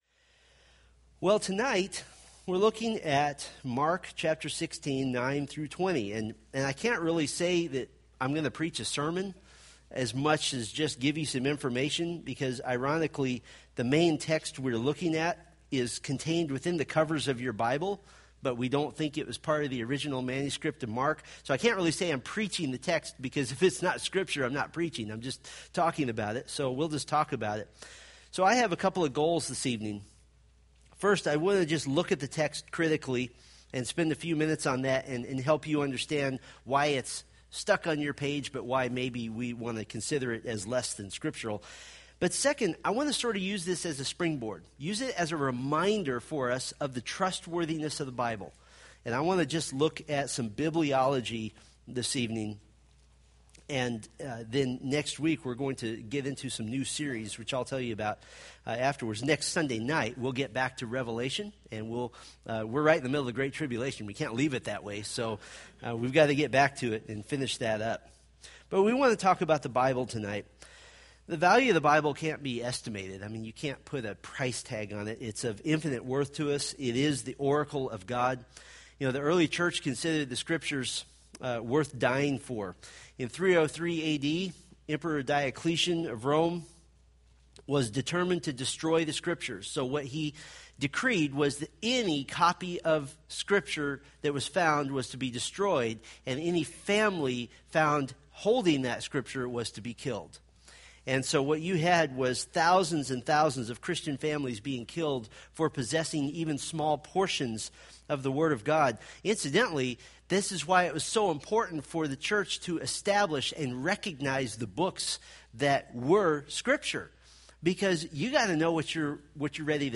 Mark Sermon Series: Gospel of Mark Download